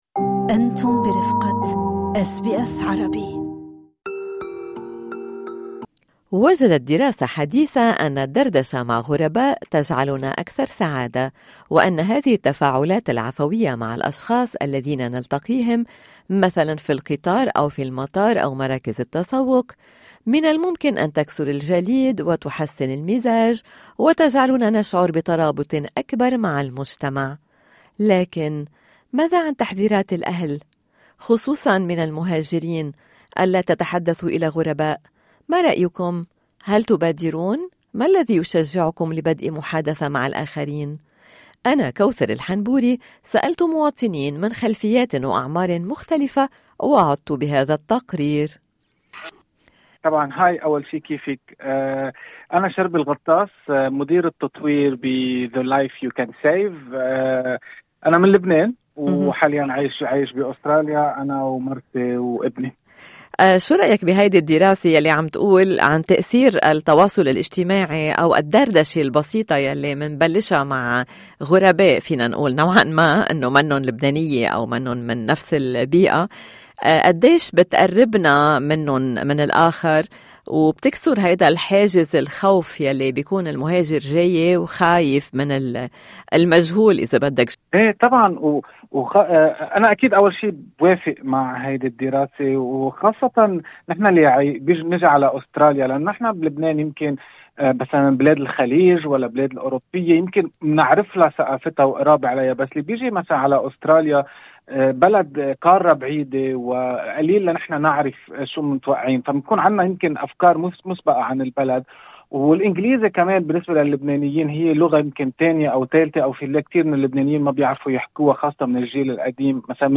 تحدثت الى مواطنين من أعمار وجنسيات مختلفة ومع طبيبة نفسية...